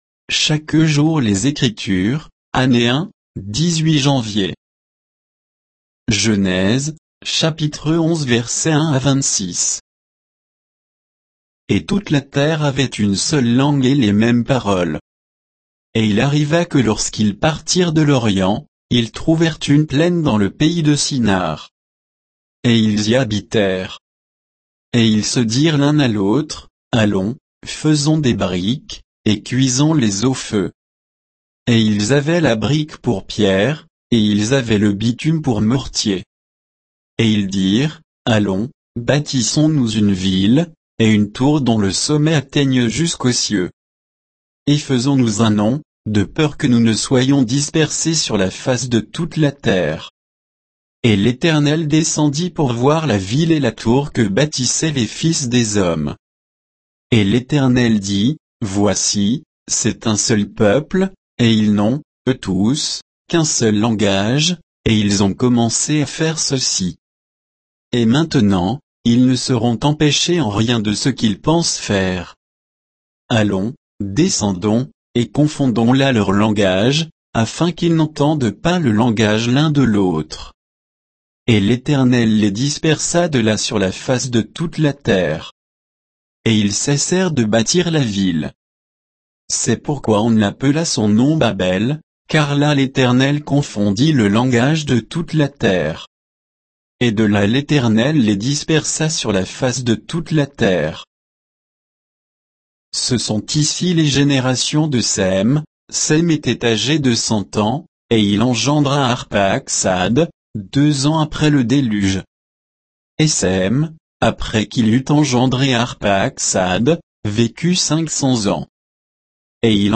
Méditation quoditienne de Chaque jour les Écritures sur Genèse 11